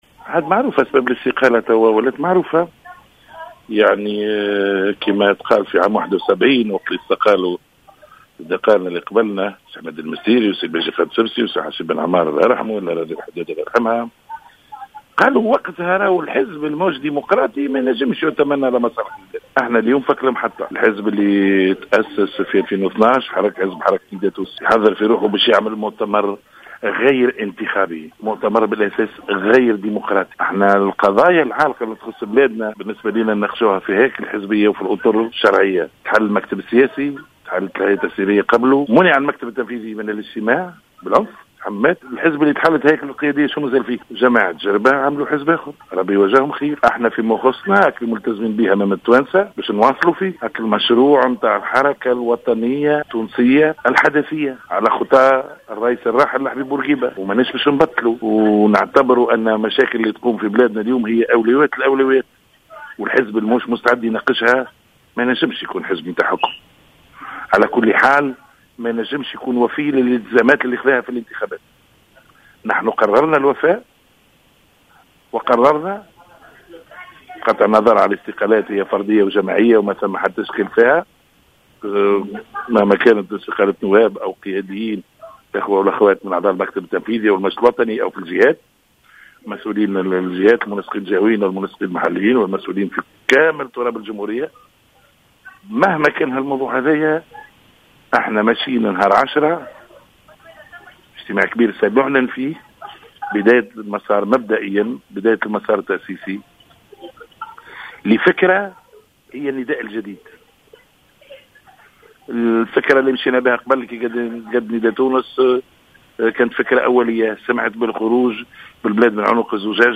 Dans une déclaration accordée mardi 5 janvier 2016 à Jawhara FM, l’ancien leader au parti Nidaa Tounes a annoncé, après avoir confirmé sa démission définitive, la constitution d’un « Nouveau Nidaa ».